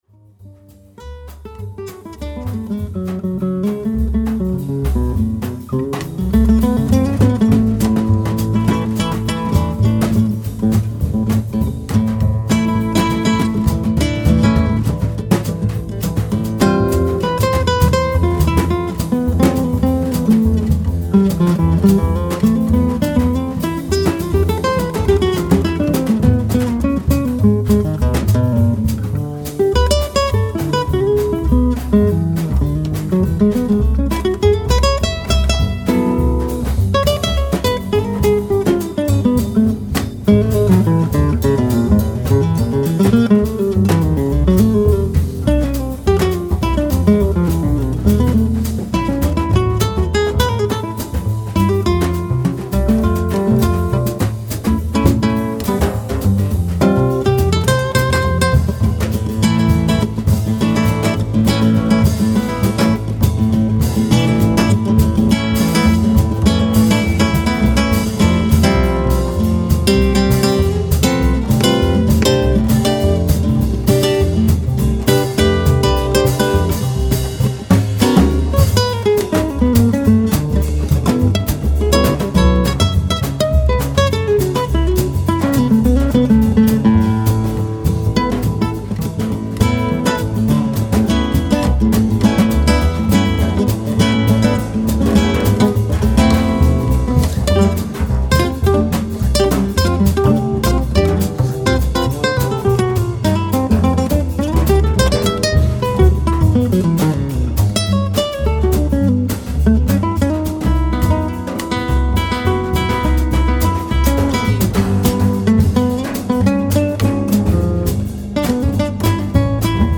contrabbasso
chitarra acustica
percussioni
Registrato in presa diretta